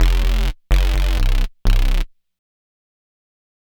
bass02.wav